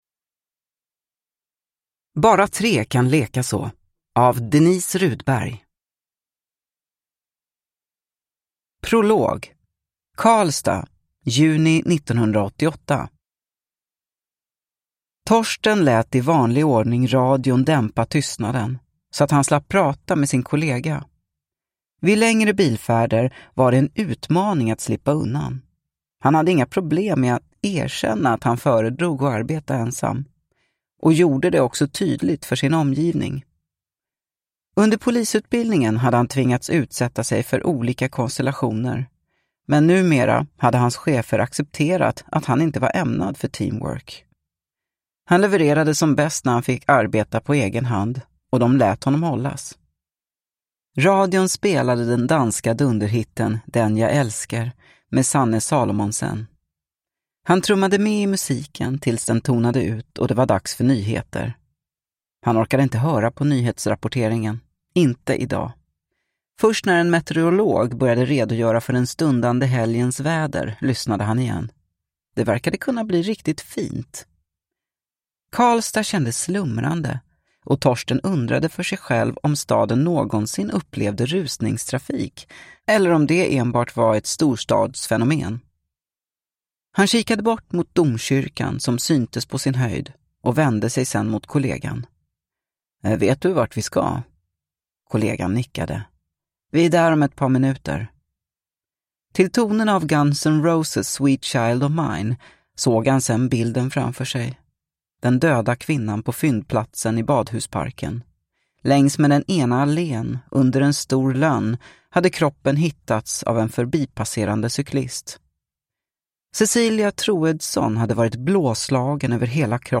Bara tre kan leka så – Ljudbok
Deckare & spänning Deckare & spänning - Ljudböcker Njut av en bra bok Visa alla ljudböcker